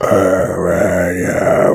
spawners_mobs_mummy_spell.1.ogg